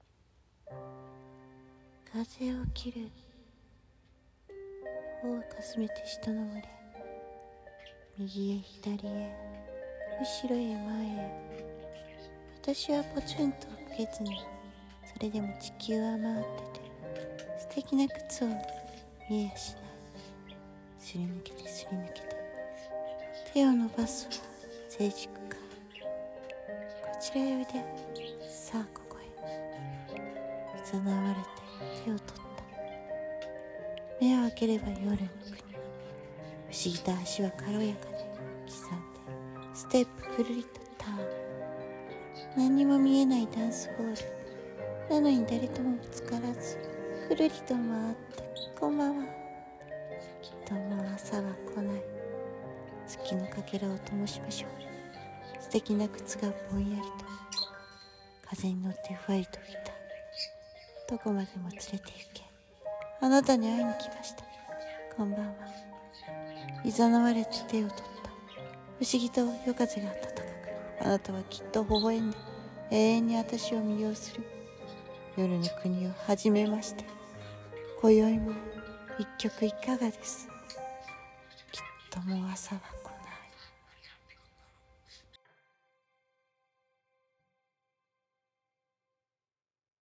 さんの投稿した曲一覧 を表示 【 夜の国 】不思議 朗読 声劇